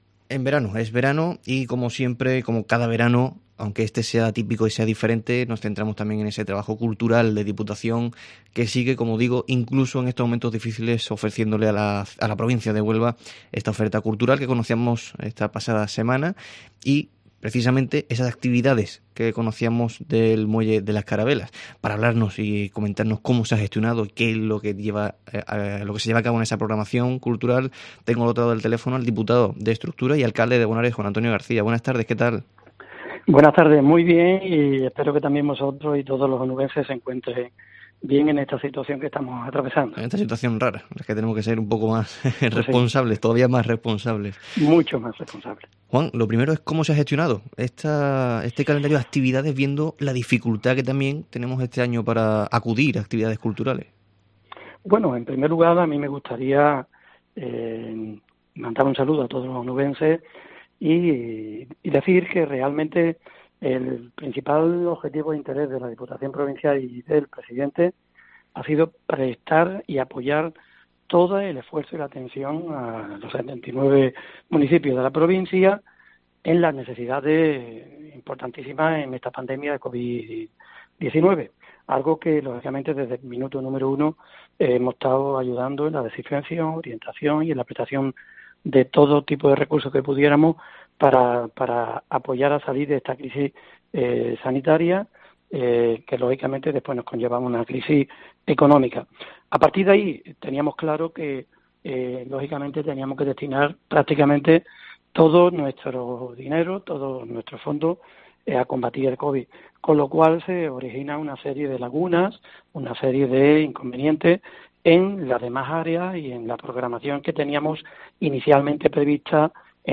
Hemos desgranado, en el Herrera en COPE Huelva de hoy, el programa de actividades del Muelle de las Carabelas y el cine en los pueblos con Juan Antonio García, diputado provincial.